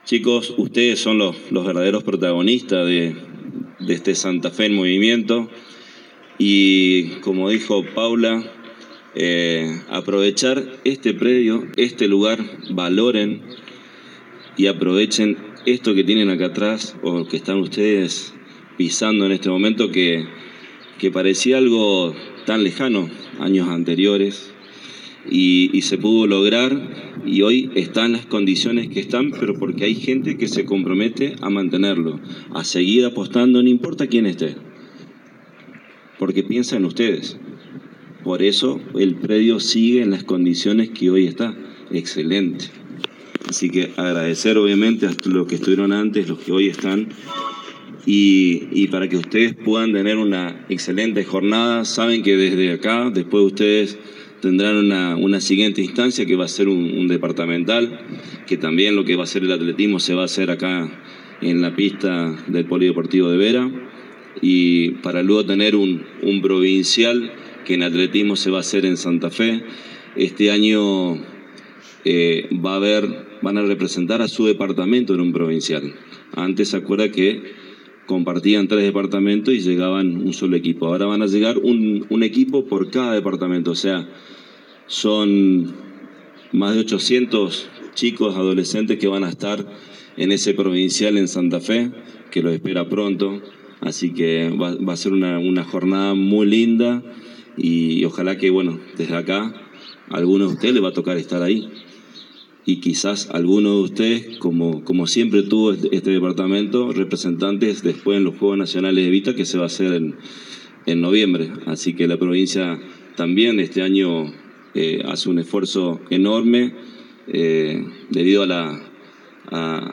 El acto de apertura tuvo lugar en el polideportivo de la Ciudad de Vera para la instancia local de las distintas diciplinas deportivas que contempla el programa.